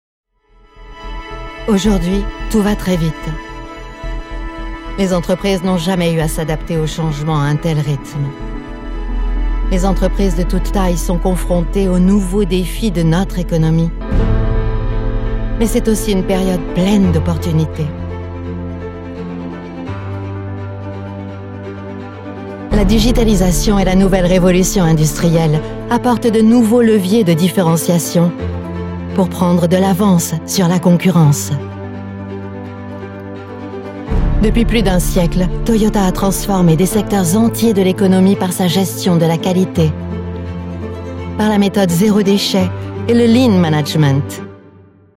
Female
French (Native)
Approachable, Confident, Corporate, Friendly, Reassuring, Versatile, Warm
Microphone: Neumann TLM 103
Audio equipment: RME Fireface UC, separate Soundproof whisper room